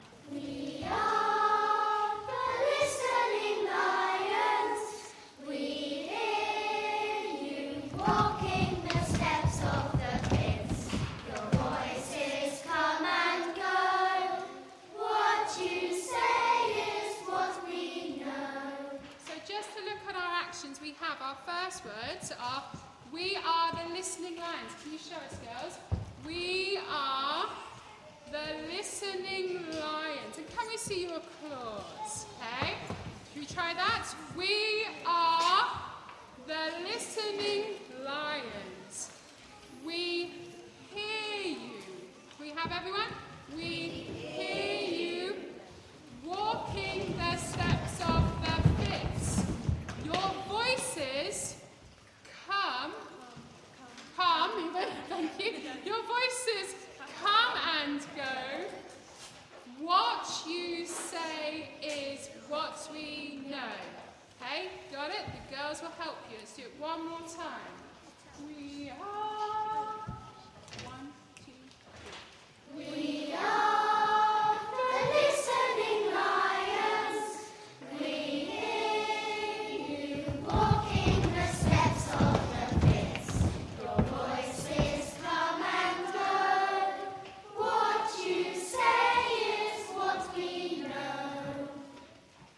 Singing History Concert 2016: Fitz Lions 1
The introductory song is a round based on the main concept of the poem